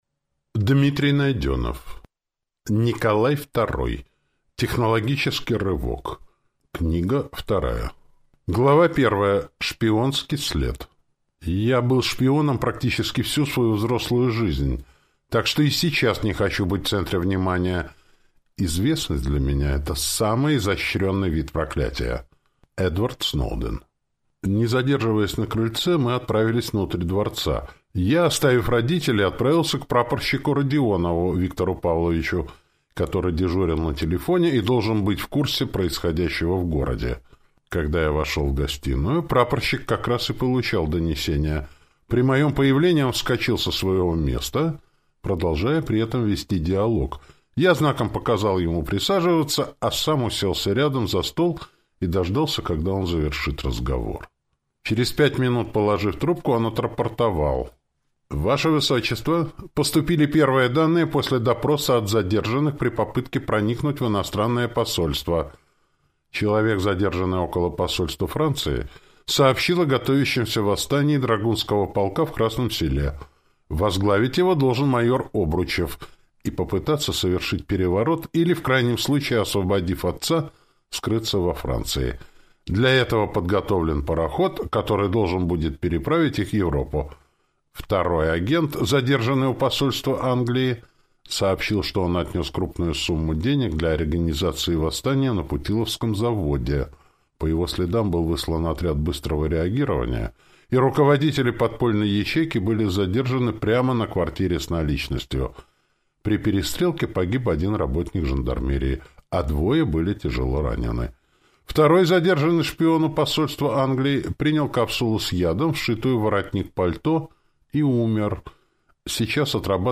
Аудиокнига Николай Второй. Технологический рывок. Книга вторая | Библиотека аудиокниг